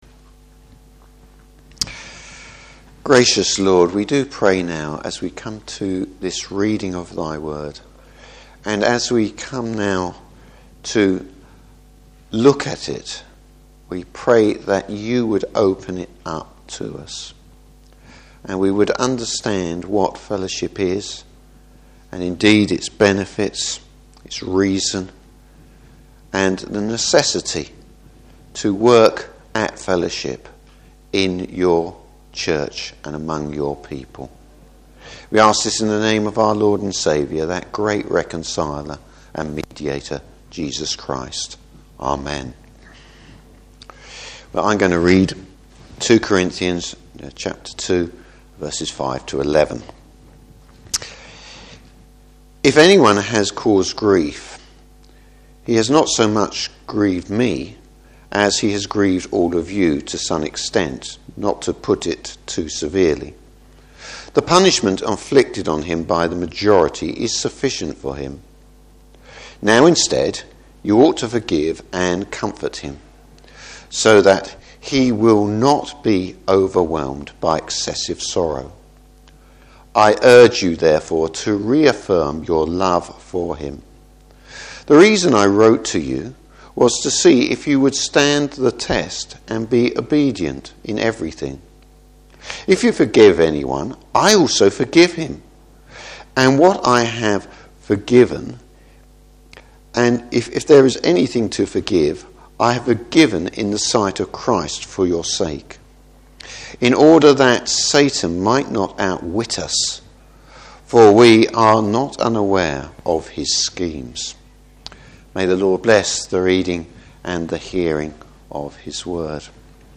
Service Type: Morning Service Restoring the repentant sinner.